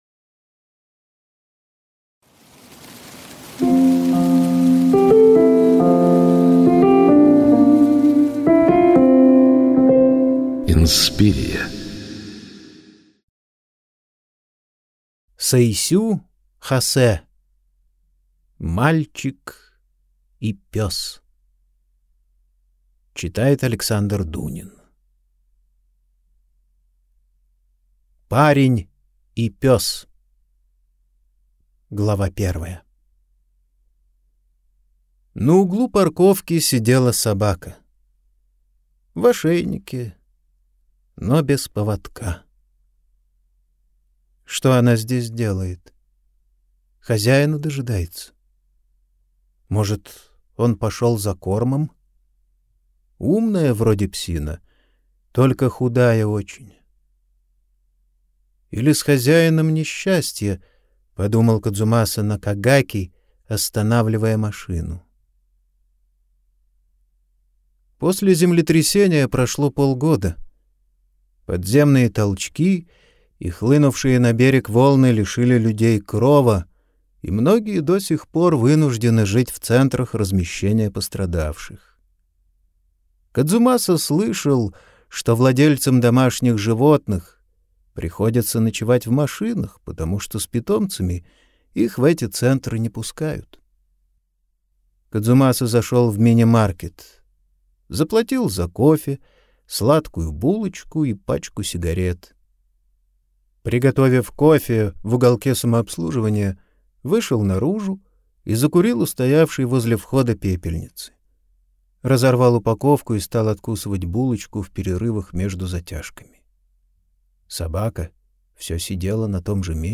Аудиокнига Мальчик и пёс | Библиотека аудиокниг